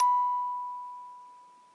八音盒单音 " 06 a6
Tag: 俯仰-A6 单音符 音乐盒